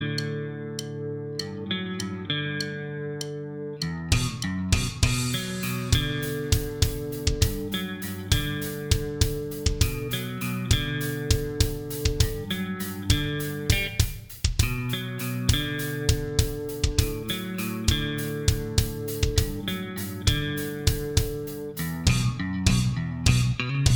Minus All Guitars Except Acoustics Rock 7:24 Buy £1.50